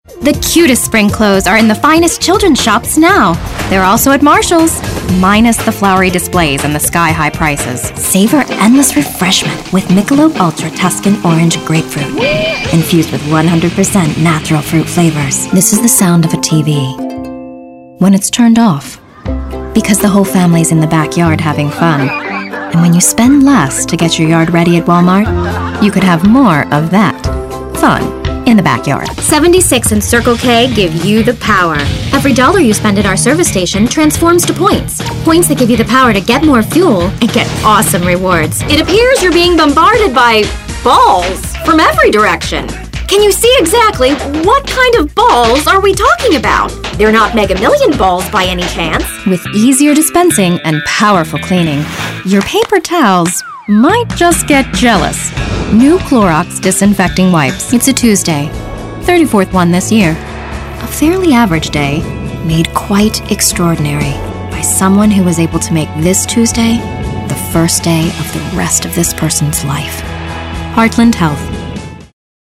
Sprechprobe: Werbung (Muttersprache):
Versatile, bright, young, corporate, professional, sultry, intelligent, relatable, storyteller